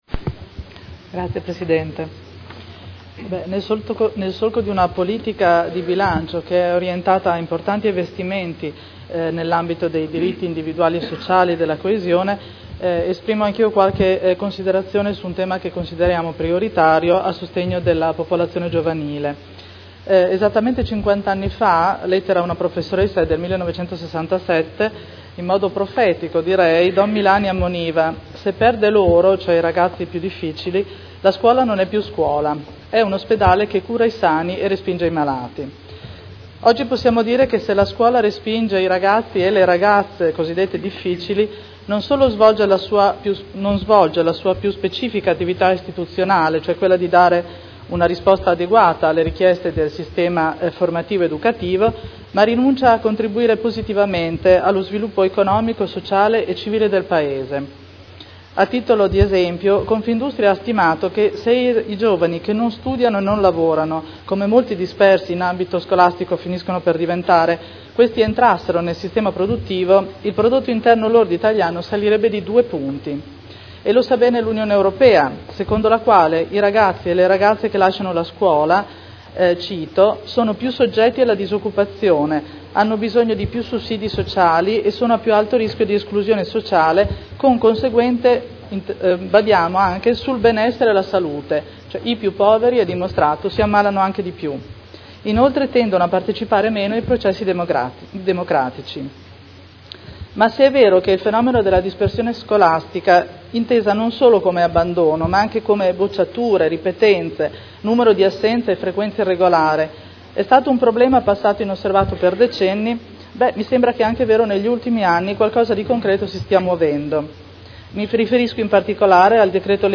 Seduta del 26 gennaio. Bilancio preventivo: Dibattito